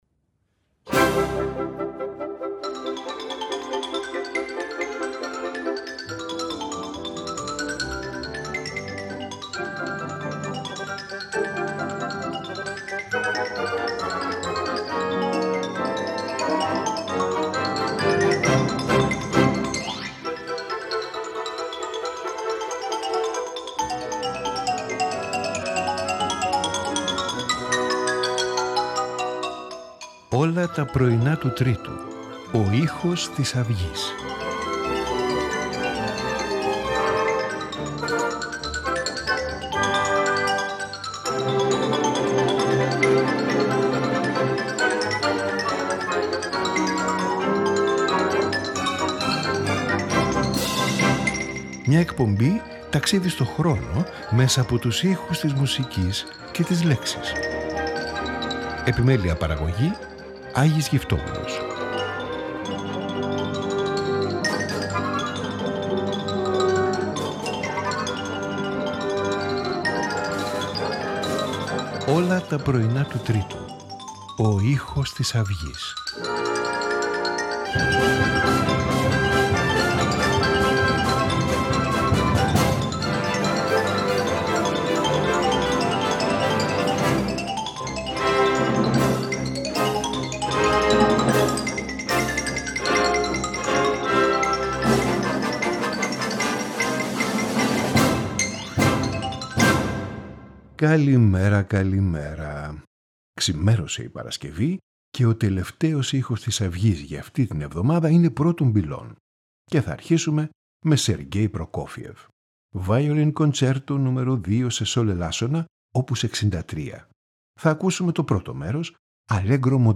Violin Concerto No 2
Clarinet Quintet in F minor
Piano Sonata No 2
Oboe Quartet